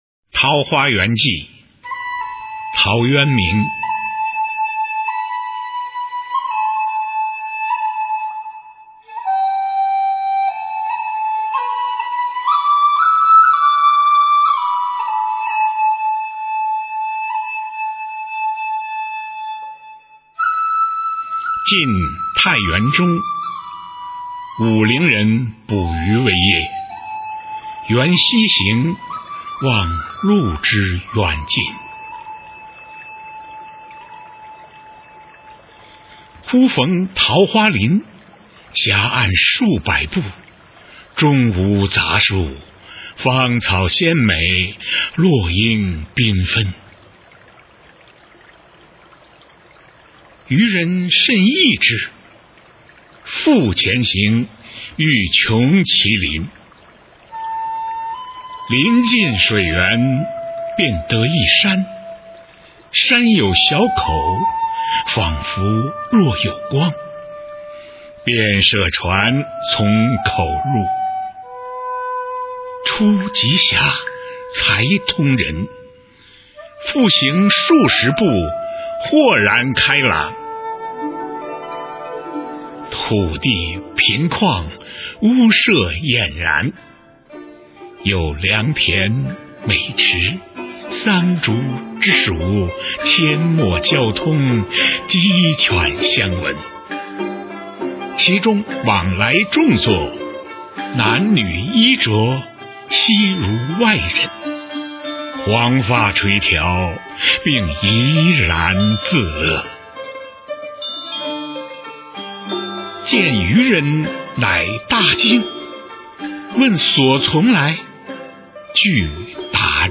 《桃花源记》原文和译文（含在线朗读）
语文教材文言诗文翻译与朗诵 初中语文八年级上册 目录